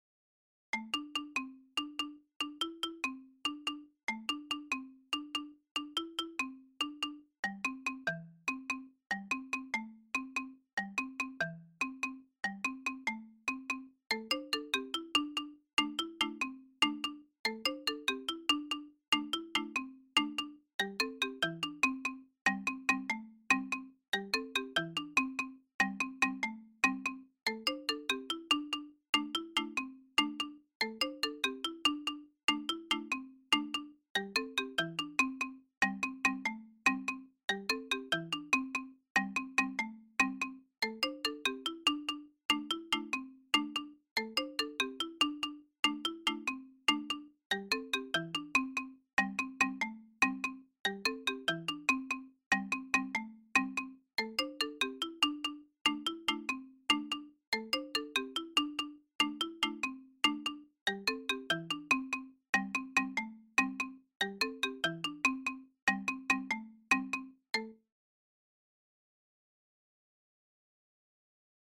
Bes - Bass + Theme 1.mp3